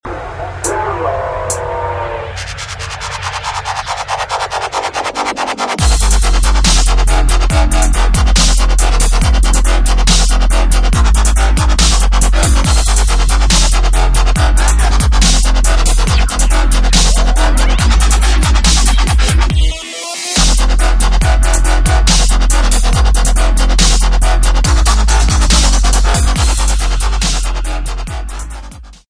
D'n'B & Jungle